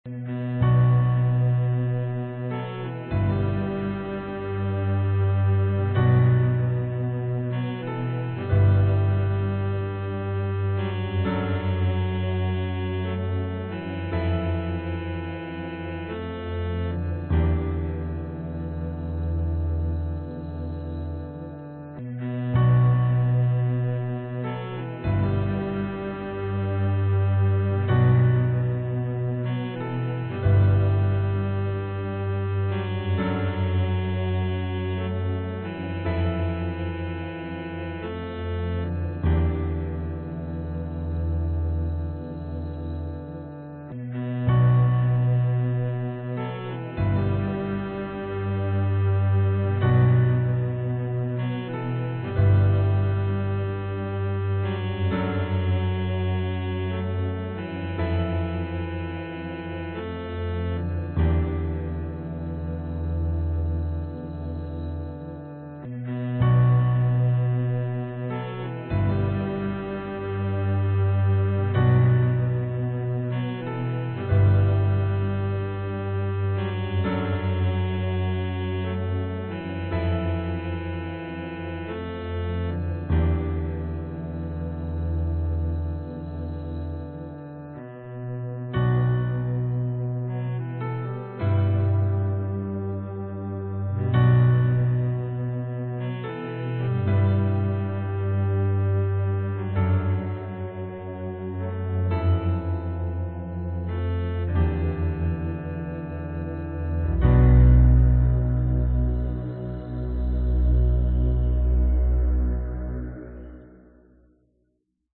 Tag: 环境 寒意 黑暗 电影 电影音乐 钢琴